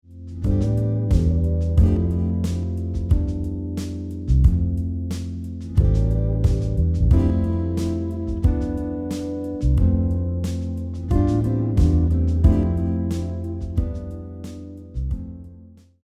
🎵 Style: Soul
🎵 Key Center: C Major or A minor
🎵 Tempo: 90 BPM
🎵 Time Signature: 4/4
🎵 Mood: Nostalgic, Dreamy, Warm, Reflective